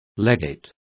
Complete with pronunciation of the translation of legates.